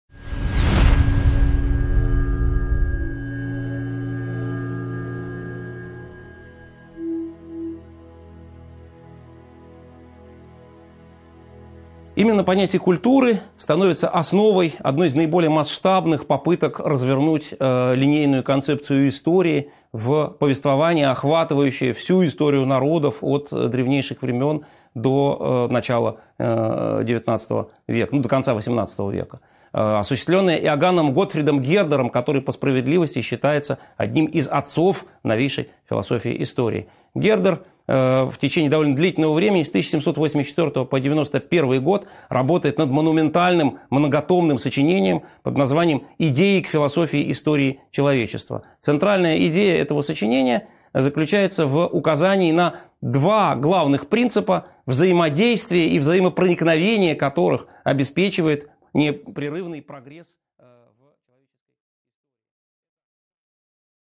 Аудиокнига 13.10 Понятие культуры и диалектическая концепция истории | Библиотека аудиокниг